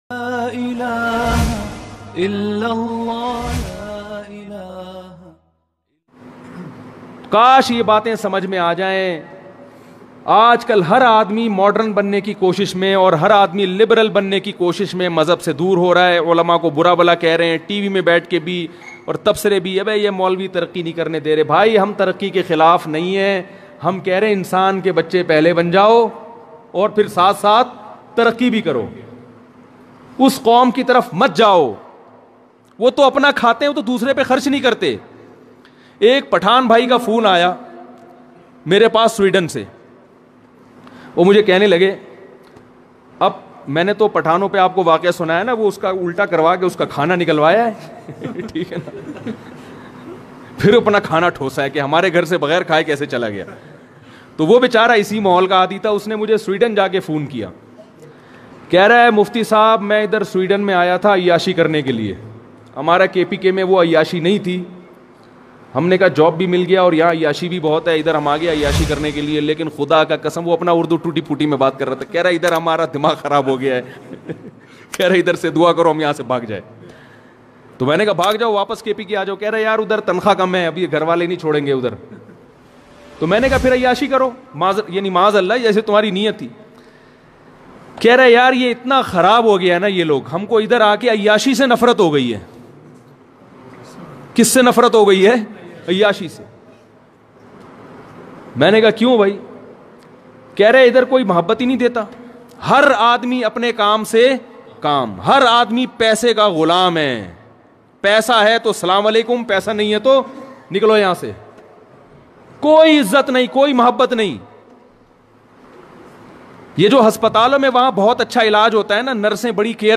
Mufti tariq masood funny bayan Latest bayan aik pathan ka waqia mp3 play online & download.